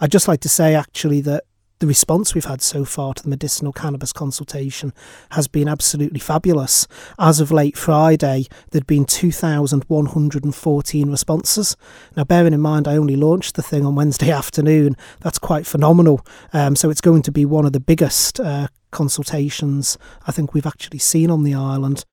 They were launched on Wednesday 6 February, and Health Minister David Ashford appeared on Manx Radio's Perspective programme the following Sunday, 10 February - amazed at the level of response, even at that time: